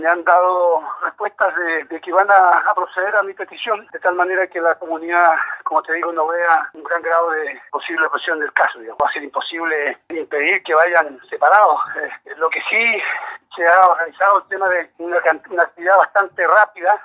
alcalde-corral.mp3